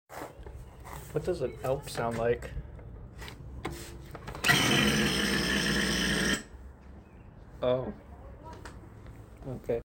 demon elk sounds sound effects free download